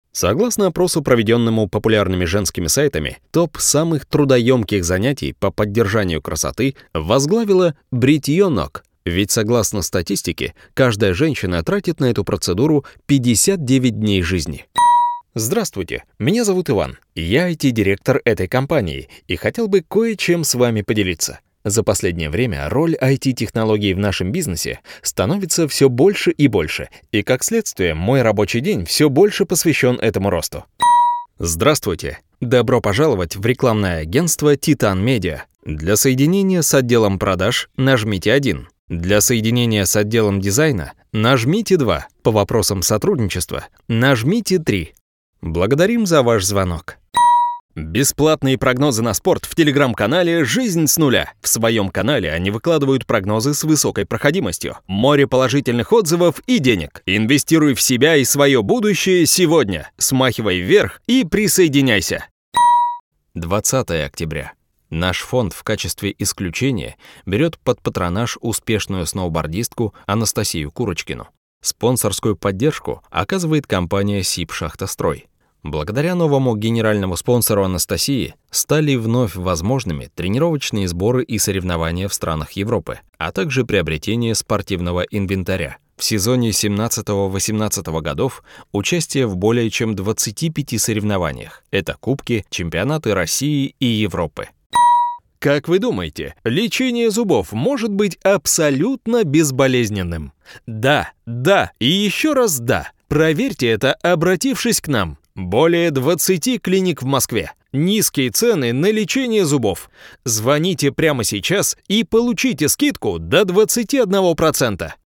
Тракт: Neumann TLM102, DBX 376, Roland Quad-Capture, KRK Rokit 6 G3
Демо-запись №1 Скачать